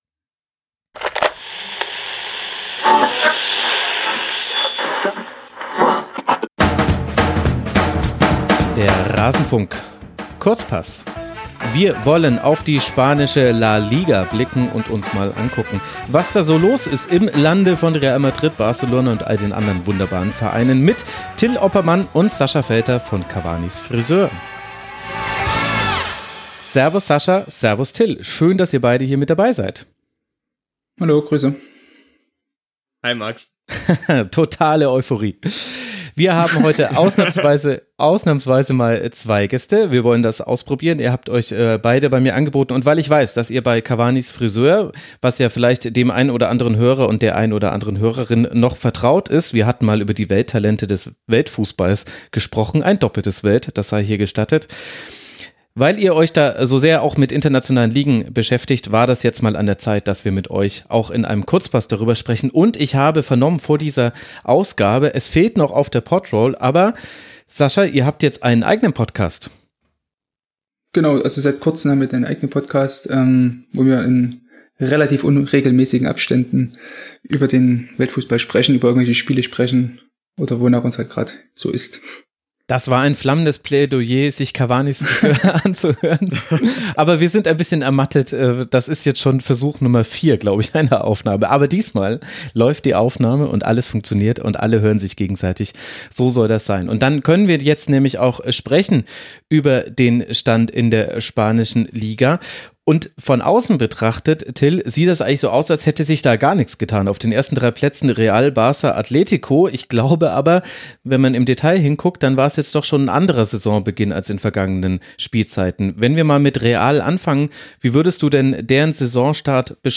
Wir blicken auf die spanische Liga und alles Bemerkenswerte, das sich dort tut. Ein Gespräch über altbekannte Gesichter bei Real, ein wackliges Barca, das atleticoste Atletico der jüngeren Geschichte, Trainerpossen bei Valencia und vor allem Marc-And